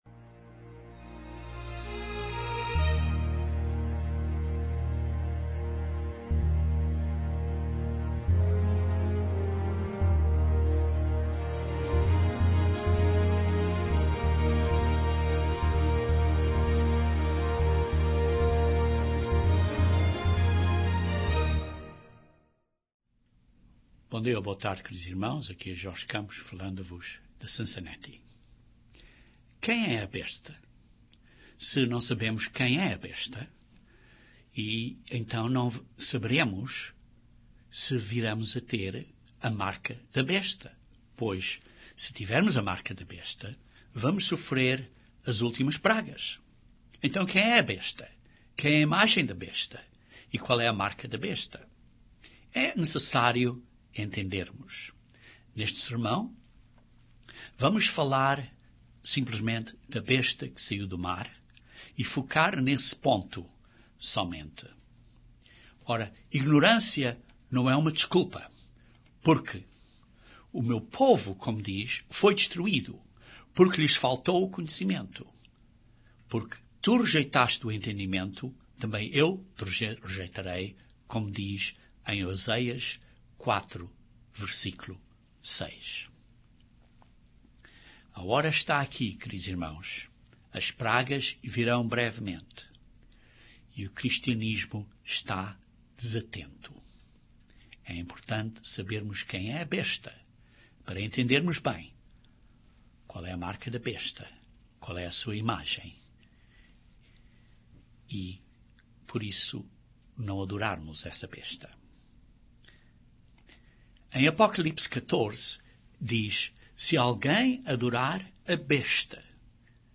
O que são as 7 cabeças e os 10 chifres? Este sermão demonstra de sua própria Bíblia a interpretação de quem é a Besta, o que é a sua imgaem e o que é a marca da Besta.